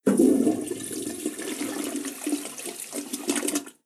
SFX toilet flush